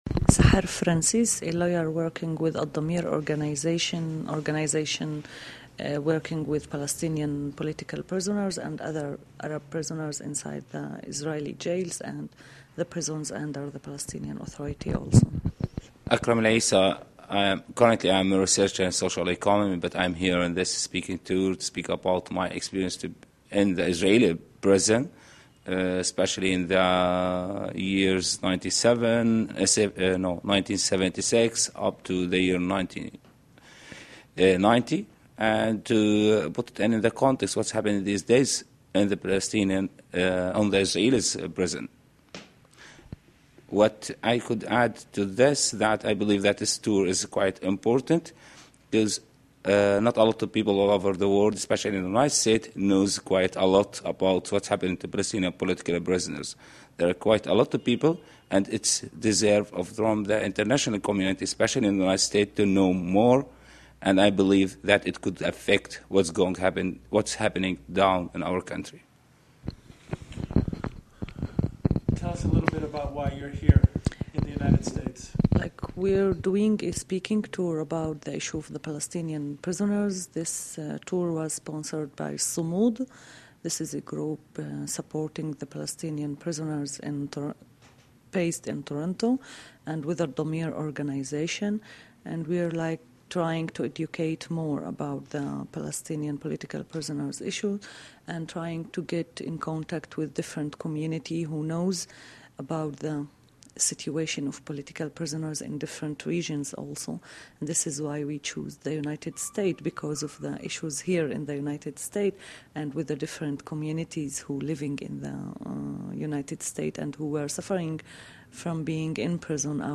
This is an interview